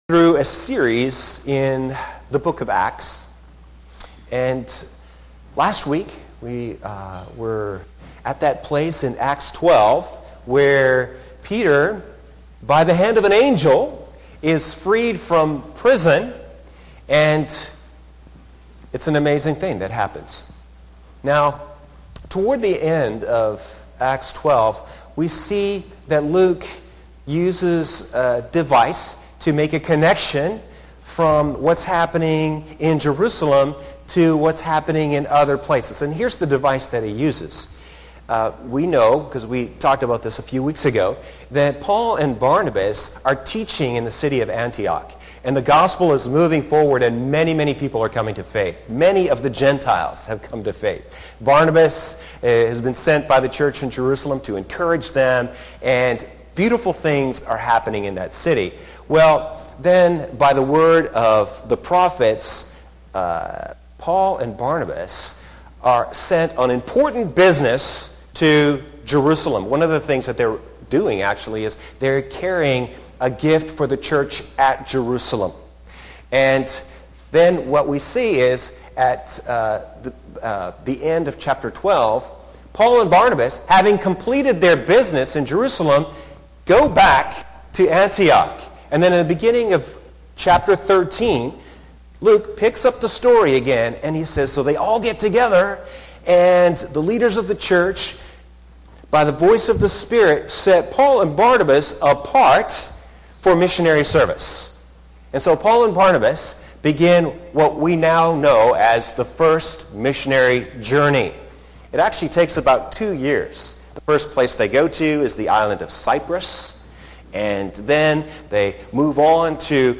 Sermon 11-19-17